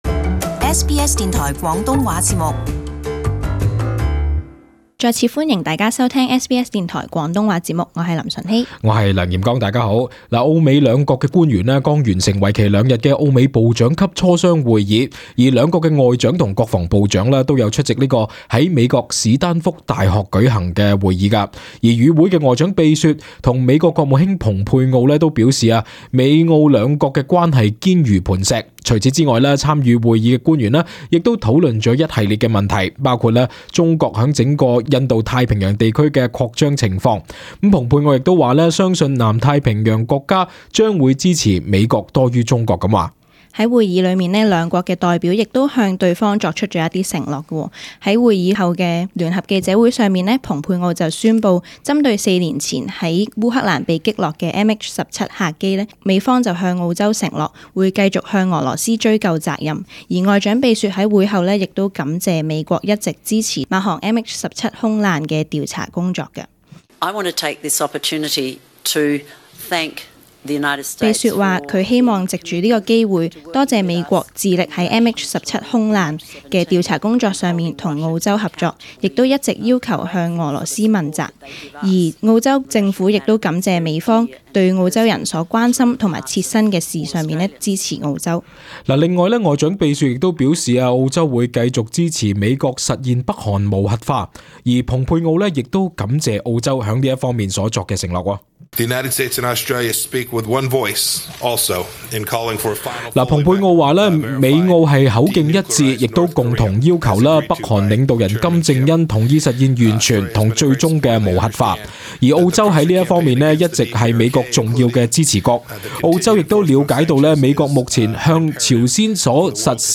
【時事報導】澳美外交領導人強調兩國關係穩固